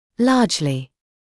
[‘lɑːʤlɪ][‘лаːджли]весьма, значительно, сильно; в значительной степени; главным образом